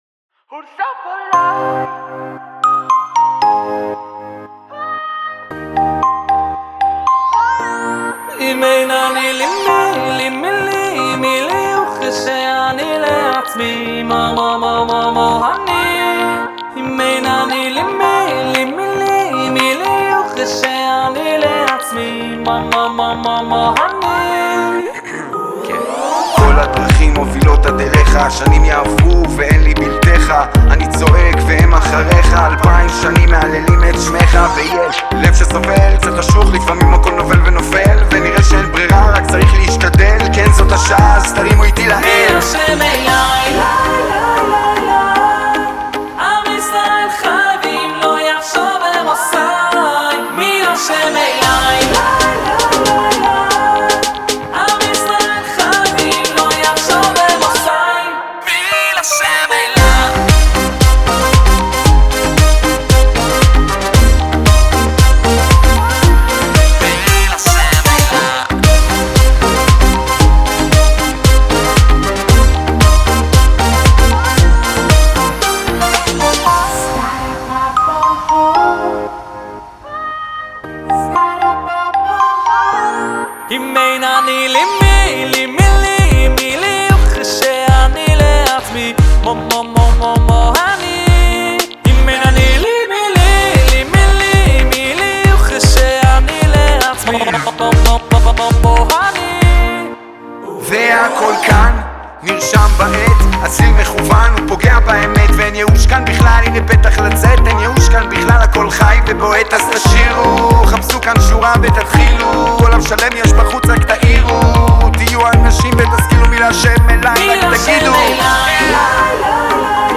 מוסיקת הפופ החדשנית במגזר הדתי
ראפר ורוקר ישראלי.